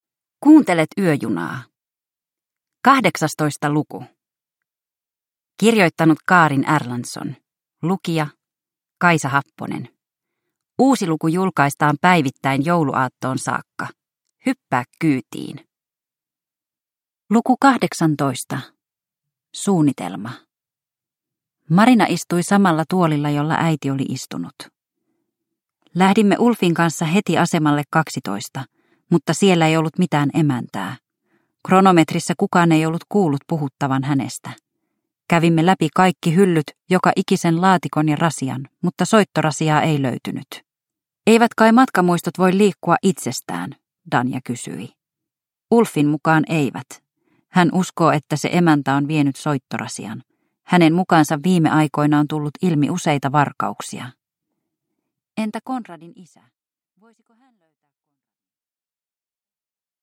Yöjuna luku 18 – Ljudbok